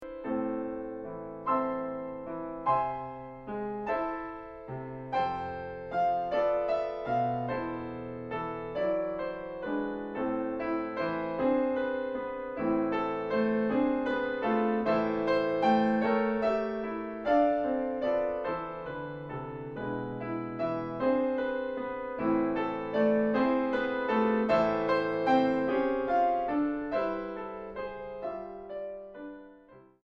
En do mayor. Con moto 1.40